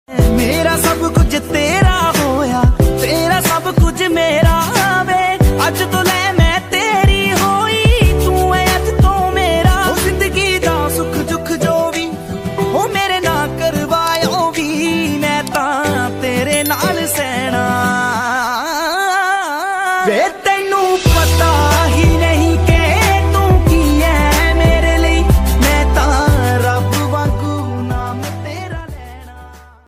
Category: Hindi Ringtones